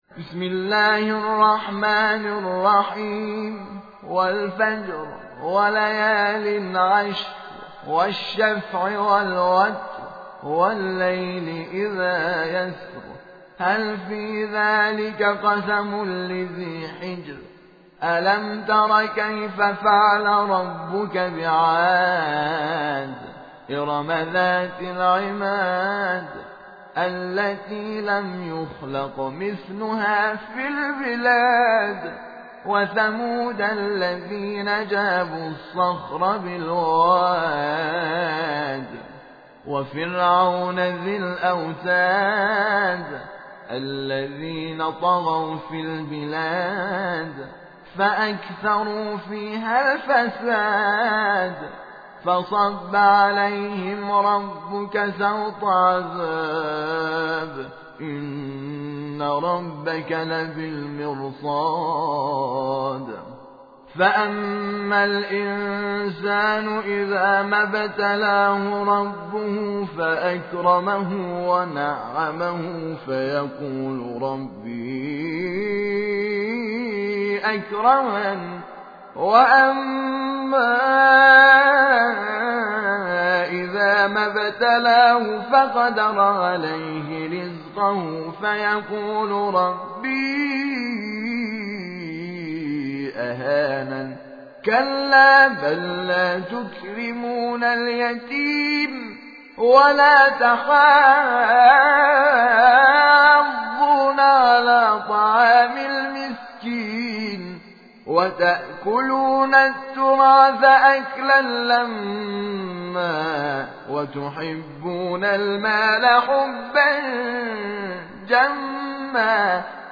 صوت | تلاوت ترتیل «سوره فجر» با صدای حامد شاکرنژاد
در قسمت پنجم، تلاوت ترتیل سوره فجر را با صدای حامد شاکرنژاد، قاری بین‌المللی کشور می‌شنوید.